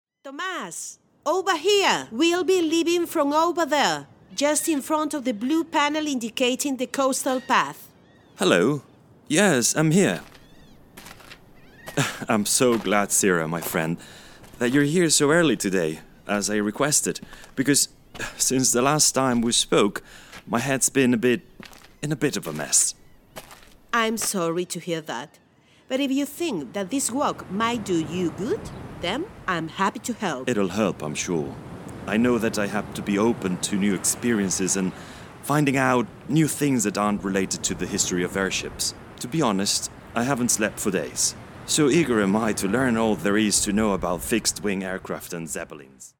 Grupo Chévere_Xove Fictional Audioguide (Extract)